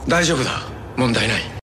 da zhang fu da wen ti nai Meme Sound Effect